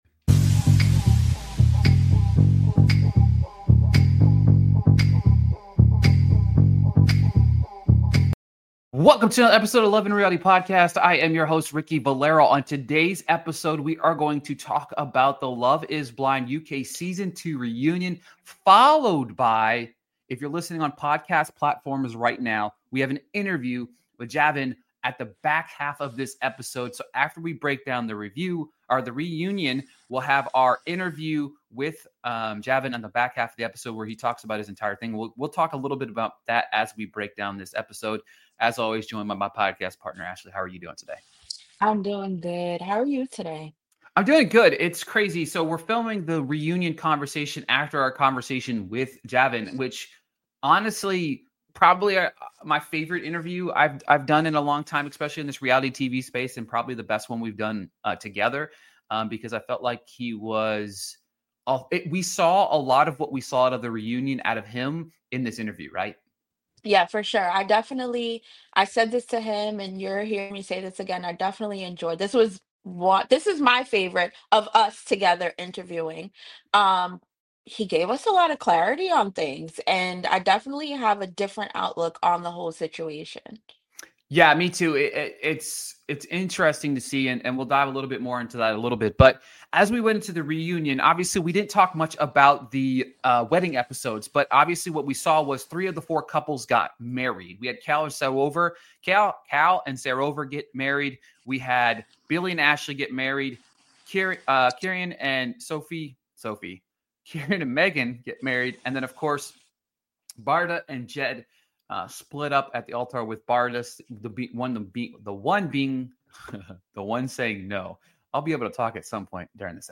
Interview: 19:57 to end